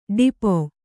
♪ ḍipo